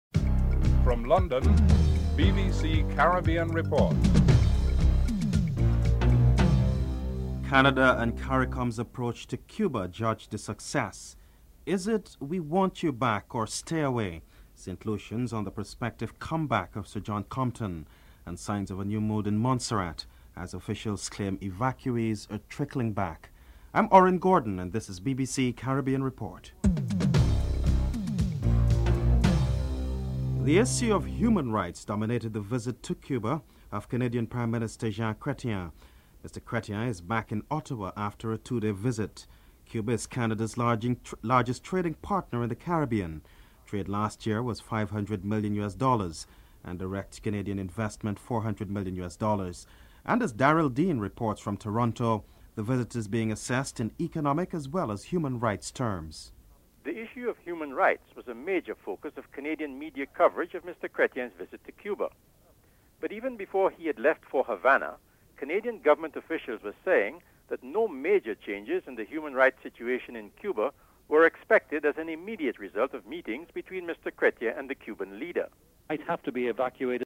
St. Lucians give their views about Compton's comeback (02:51-04:26)